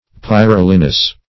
Pyrolignous \Pyr`o*lig"nous\, a.
pyrolignous.mp3